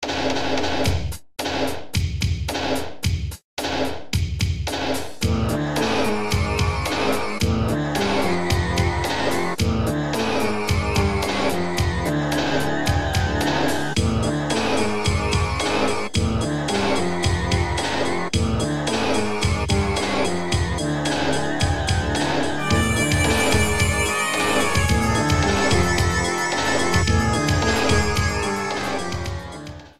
Added fade-out at the end